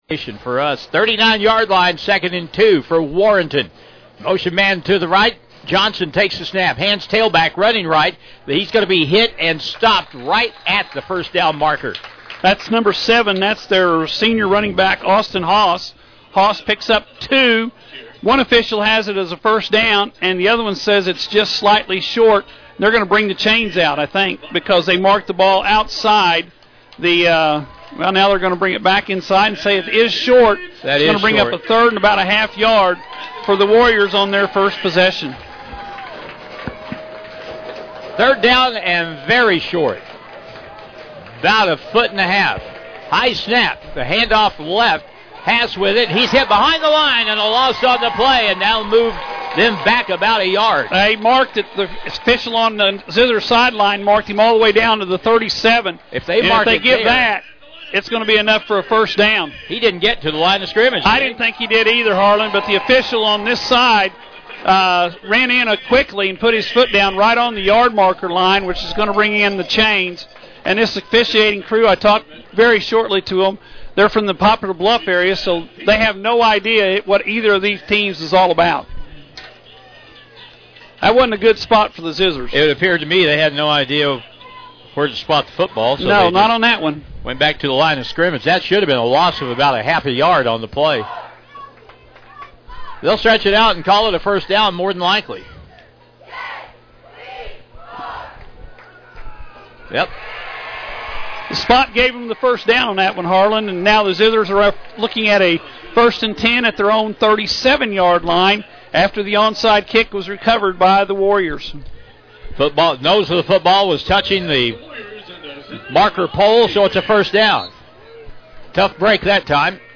West Plains Zizzers faced the Undefeated Warrenton Warriors this Saturday November 23rd, 2024 from MacDonald’s field at Zizzer Stadium. The Warriors proved the worth of their 11-0 record as they defeat the Zizzers by a Score of 28-24